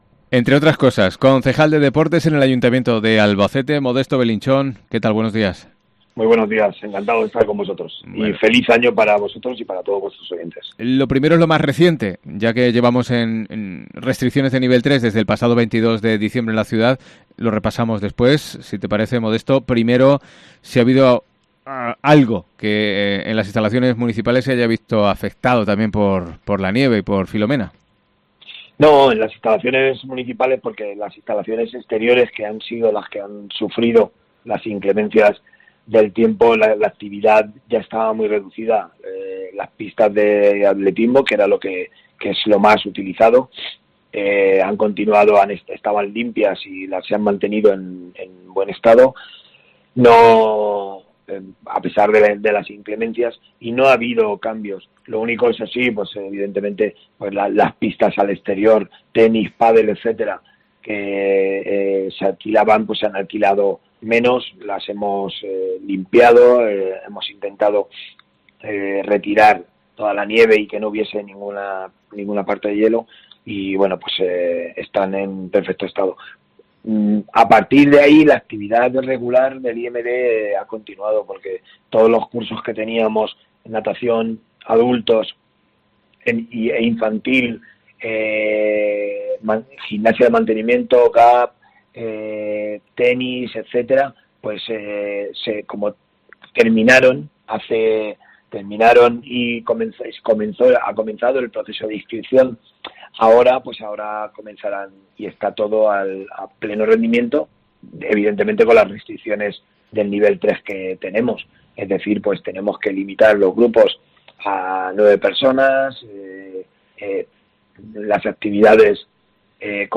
AUDIO: Modesto Belinchón, concejal de Deportes del Ayuntamiento de Albacete, repasa algunos aspectos del próximo plan de inversiones municipal
ENTREVISTA